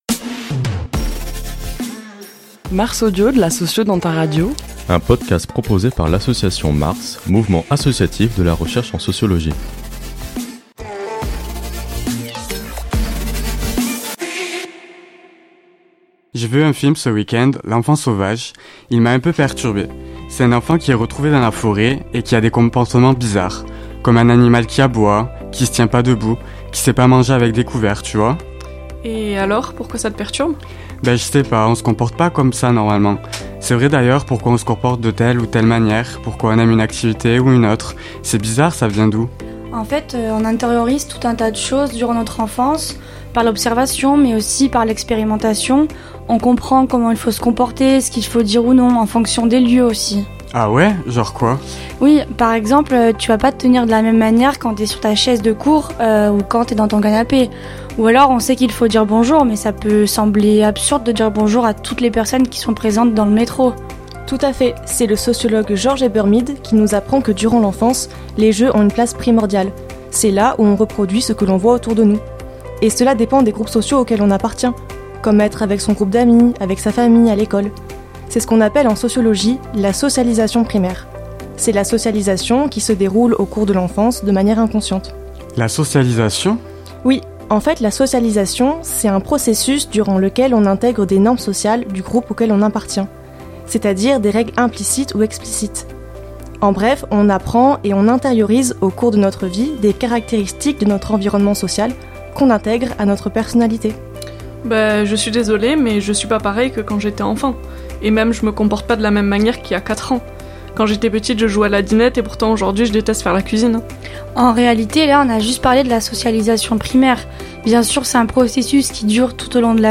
Ce mois-ci, MARS Audio vous invite à découvrir la socialisation à travers un court dialogue fictif.